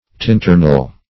Tinternell \Tin"ter*nell\, n.